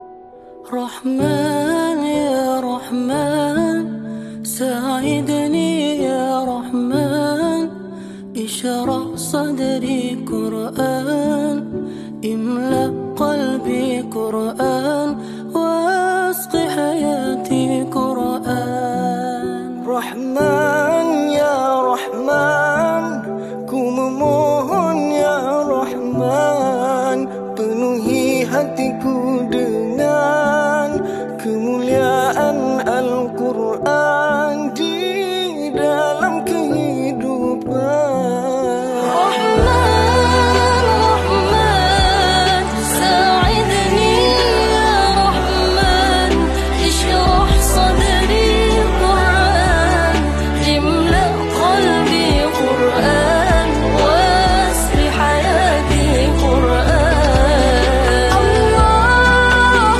🥹❤🎧 Islamic Nasheed.